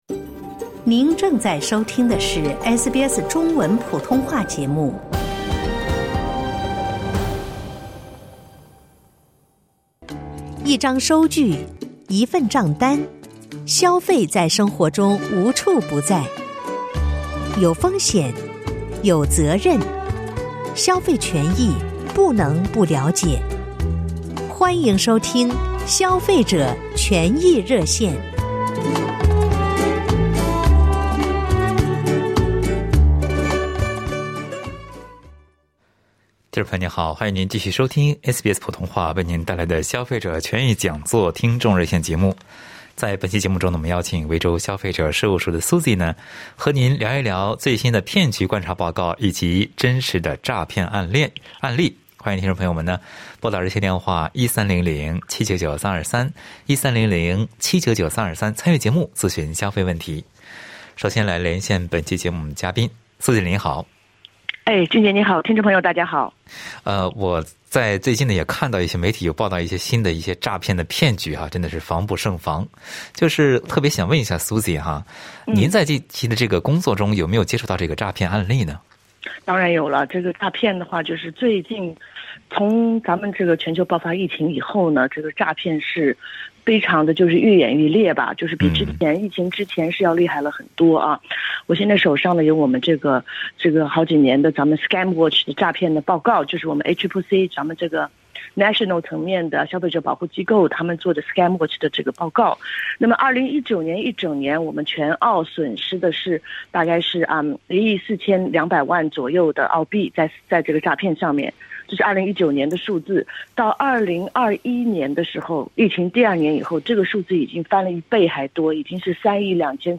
在节目中，听友们还分享和咨询了电话银行诈骗、收到催债公司账单等经历和问题。